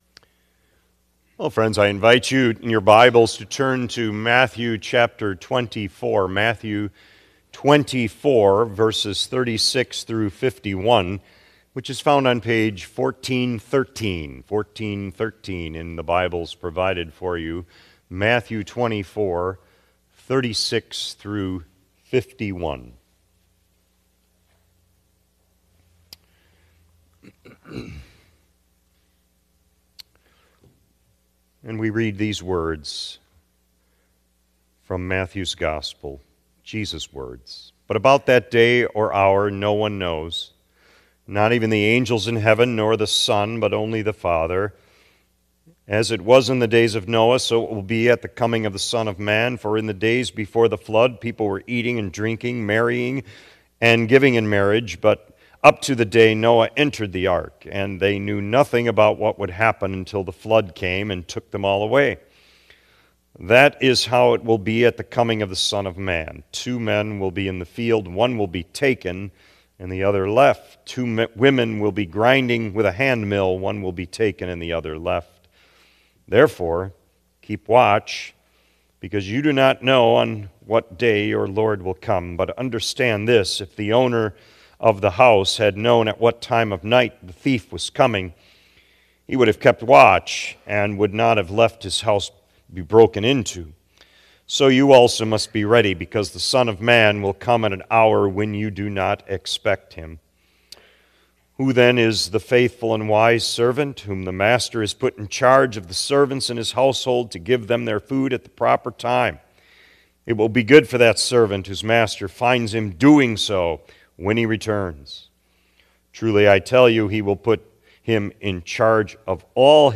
April 6 2025 P.M. Servcice